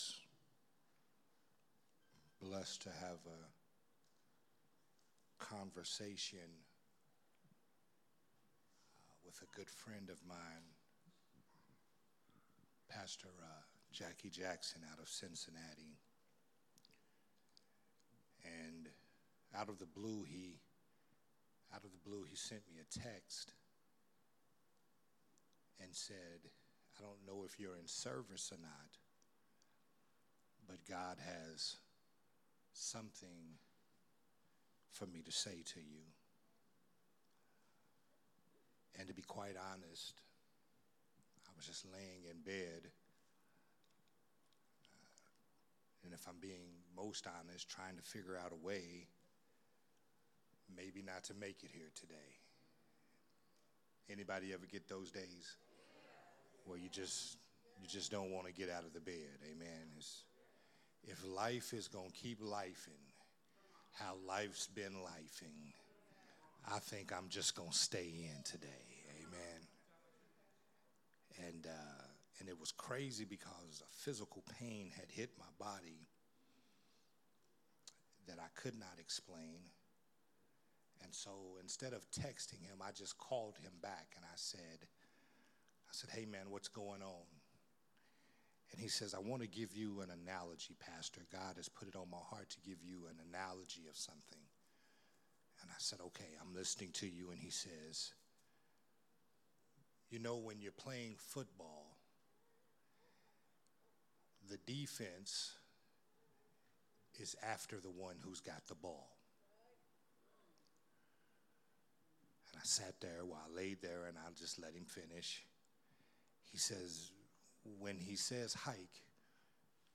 Sunday Morning Worship Service Growth Temple Ministries